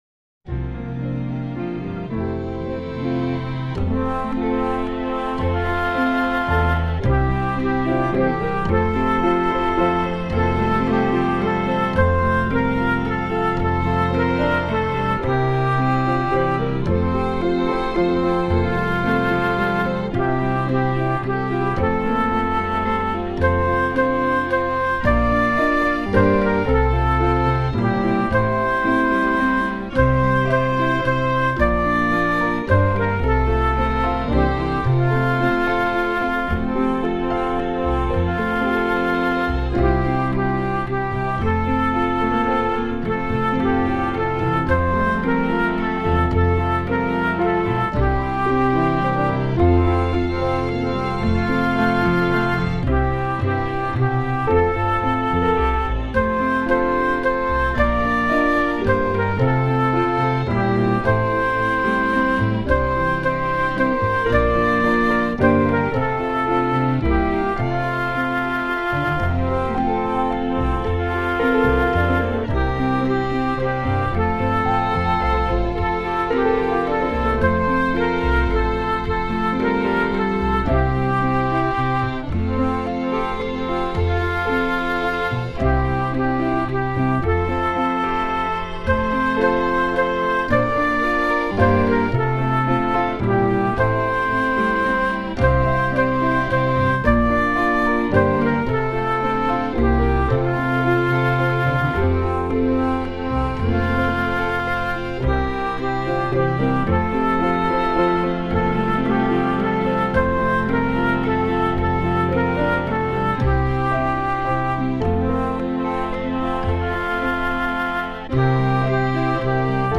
the entrance for Pentecost
Check out how happy the choir leader is with the fanfare: